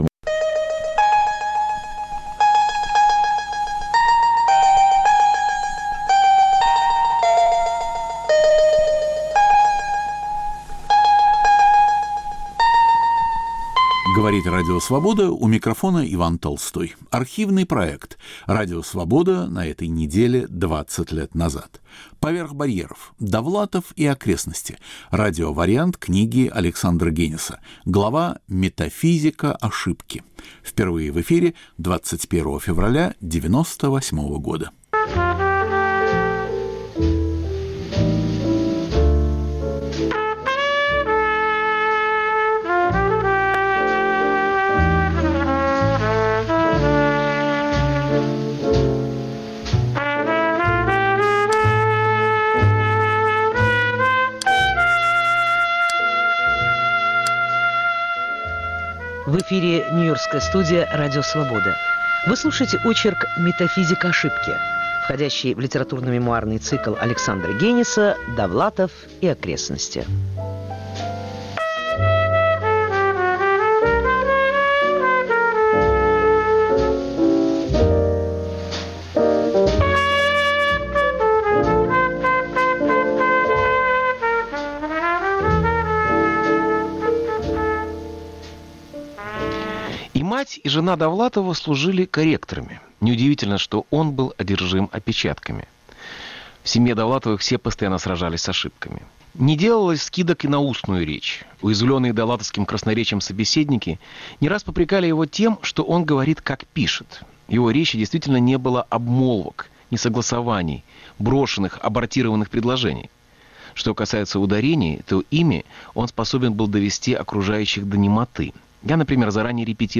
Радио-вариант книги Александра Гениса.
Три письма в "Крестьянскую газету", о вживании участников войны в мирную жизнь и другие документы. Автор и ведущий Анатолий Стреляный.